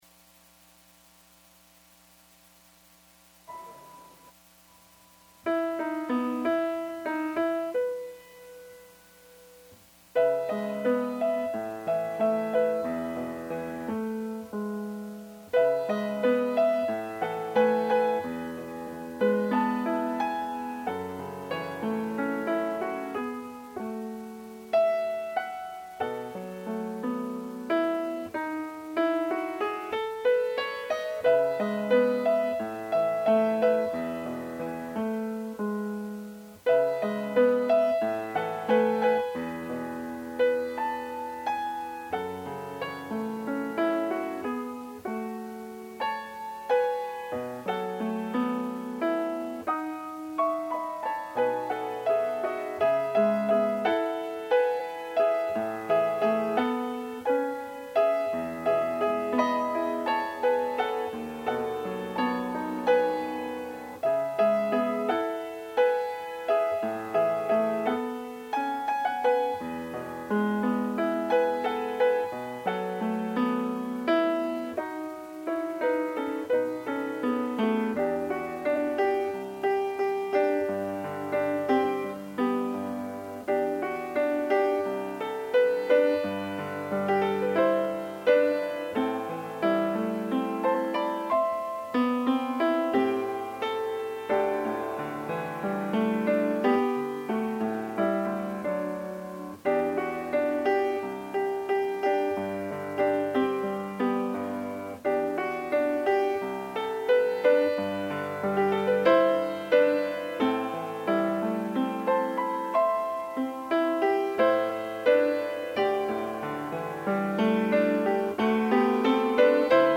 10/11/20: New Deacon Ordination Service